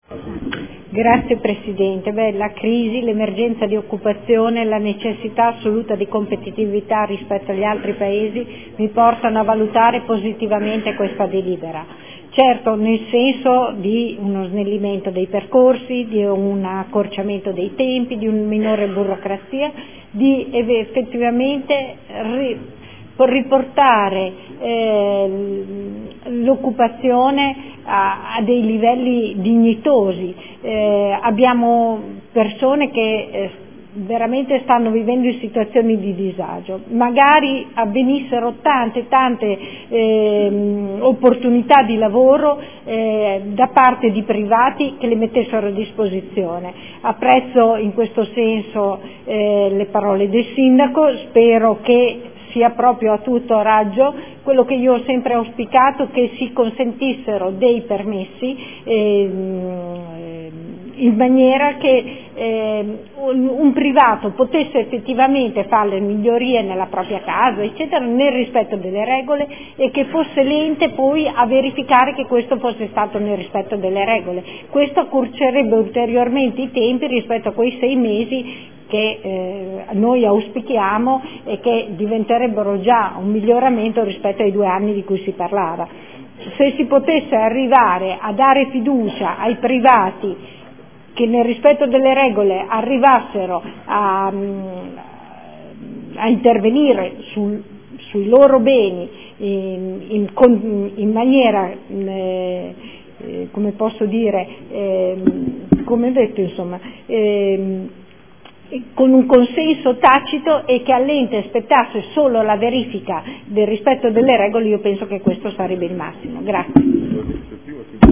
Luigia Santoro — Sito Audio Consiglio Comunale